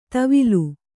♪ tavilu